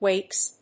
発音記号
• / weks(米国英語)
• / weɪks(英国英語)